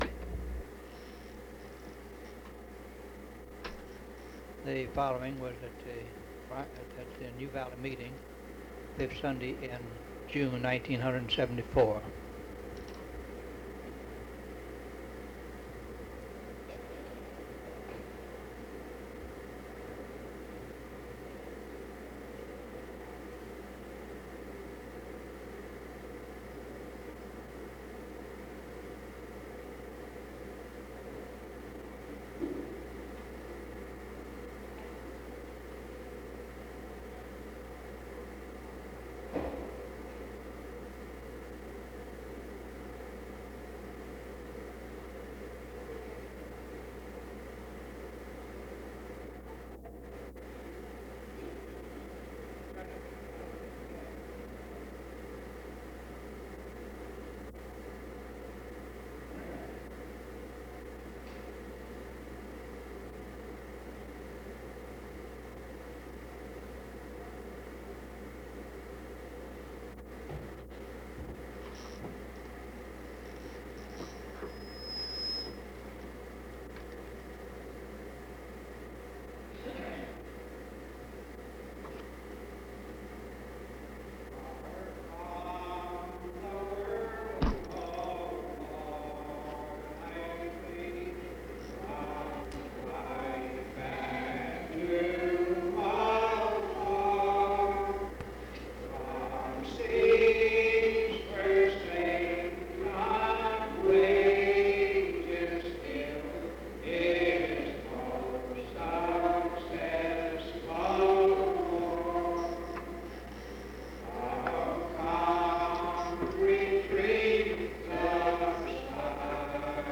sermon collection